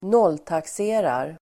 Ladda ner uttalet
Uttal: [²n'ål:takse:rar]